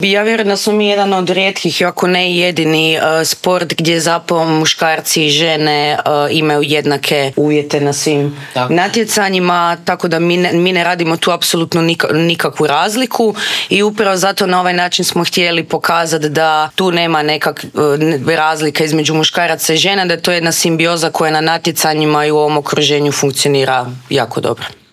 U intervjuu Media servisa o tome su pričale naše gošće